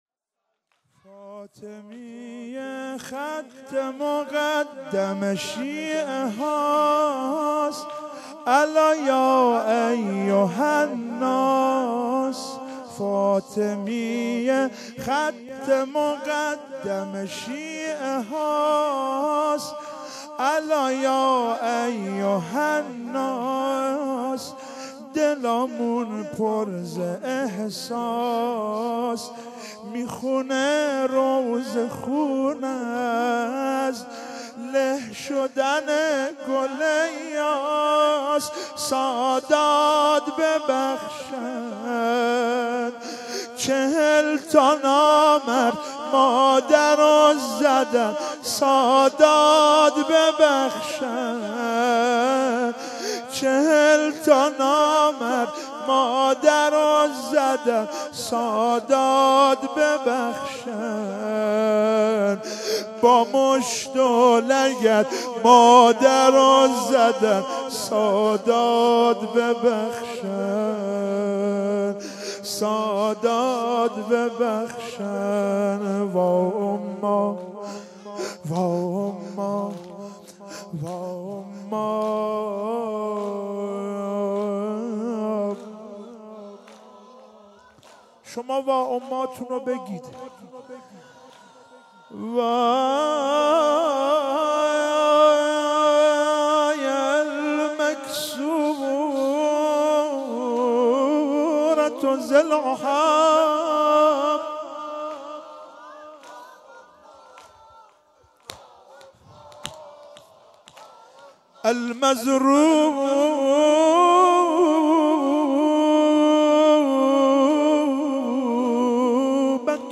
زمینه - فاطمیه خط مقدم